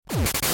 1xLSDJ, 1xVBA.